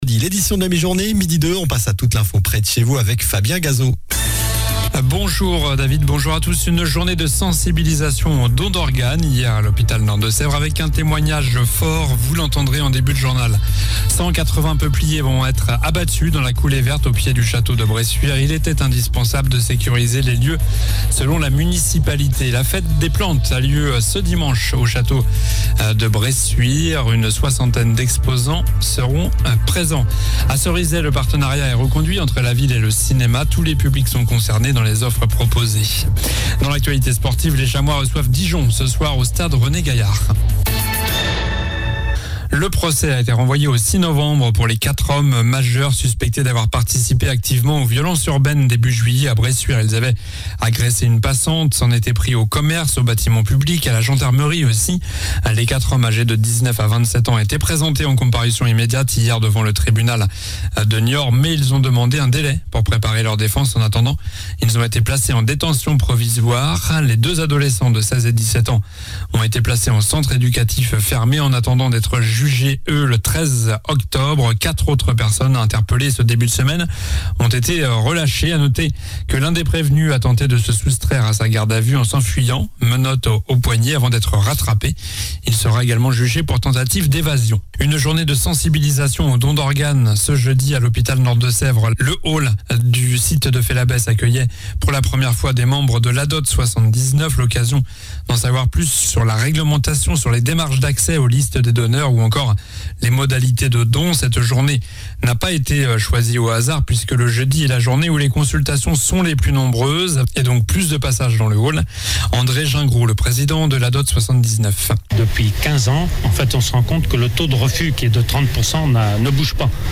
Journal du vendredi 29 septembre (midi)